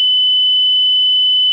LUZ FIJA DE LED - SONIDO CONTINUO
Continuo